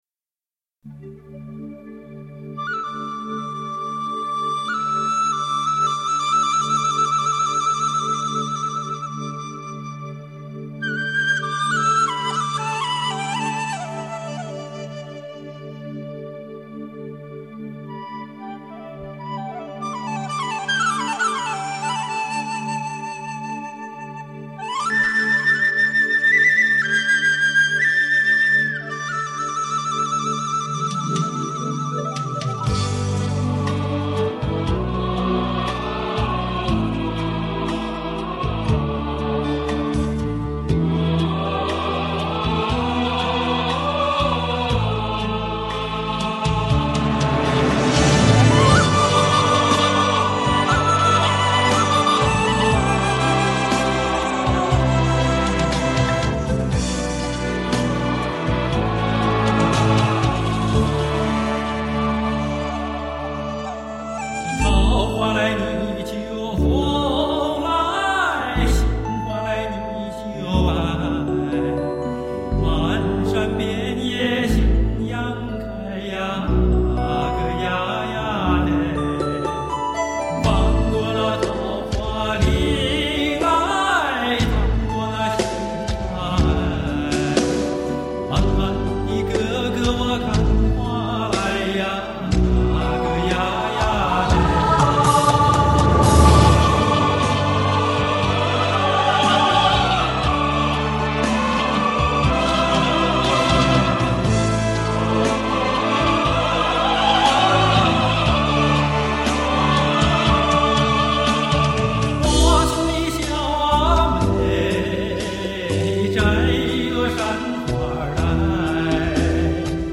请 下载听 桃花红杏花白 （山西民歌） 演唱